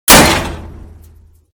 / gamedata / sounds / material / bullet / collide / metal04gr.ogg 19 KiB (Stored with Git LFS) Raw History